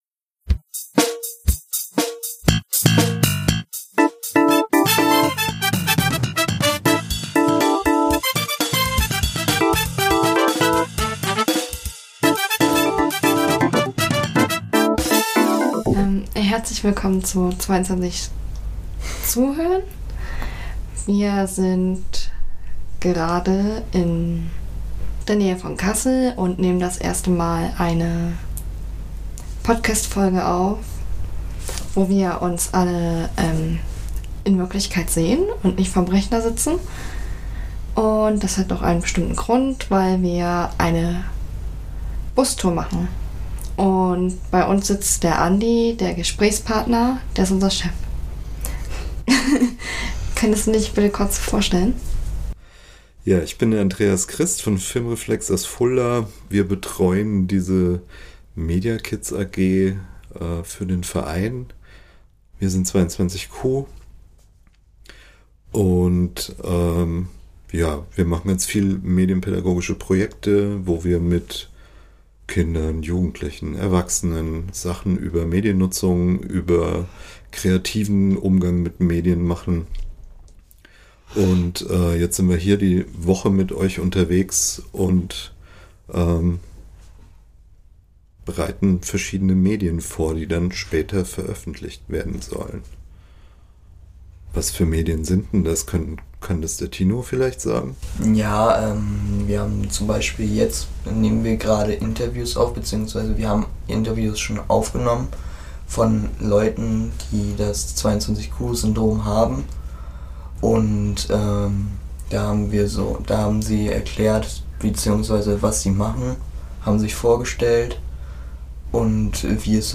Unsere erste Episode, die wir in Präsenz aufnehmen können. Auf der Bustour 2021 haben wir uns zusammen gesetzt und über das gesprochen, was wir hier eigentlich alles im Projekt machen.